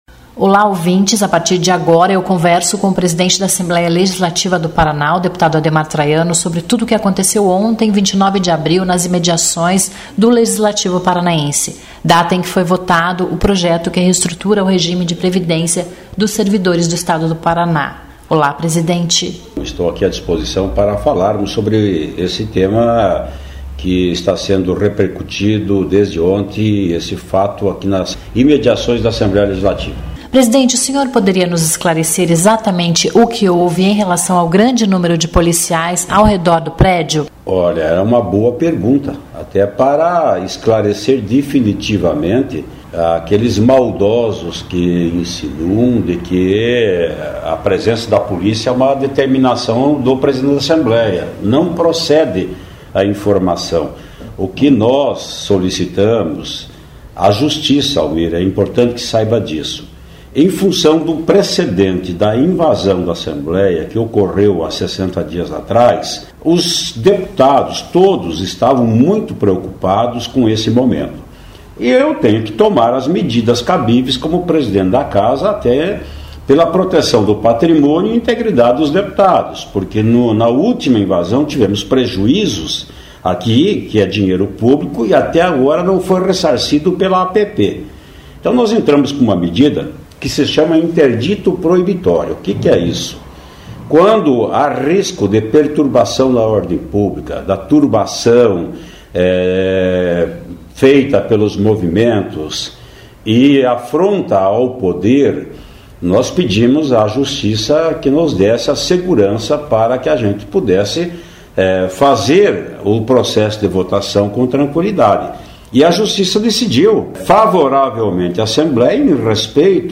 Entrevista com presidente Ademar Traiano. Ele fala sobre assuntos relacionados à sessão desta quarta-feria, entre outros.